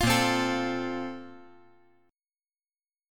A#M7sus2 Chord
Listen to A#M7sus2 strummed